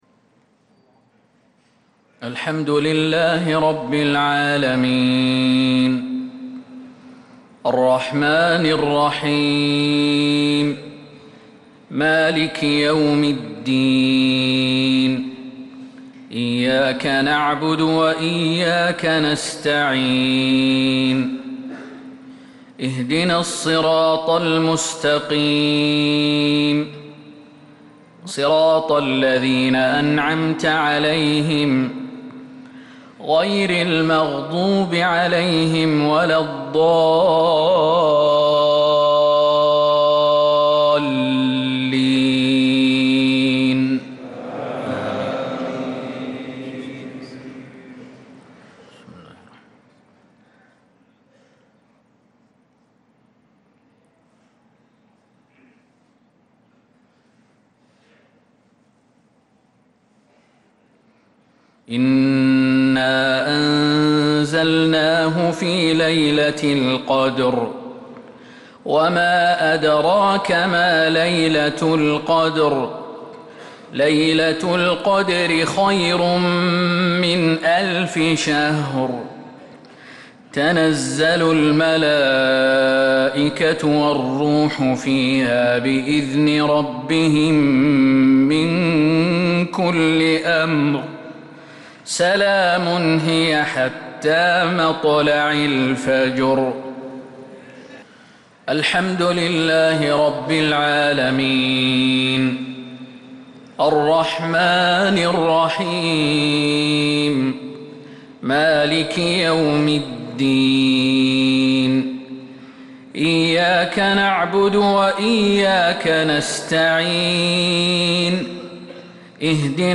مغرب الخميس 8 محرم 1447هـ سورتي القدر و الكوثر كاملة | Maghrib prayer from Surah Al-Qadr and Al-Kauther 3-7-2025 > 1447 🕌 > الفروض - تلاوات الحرمين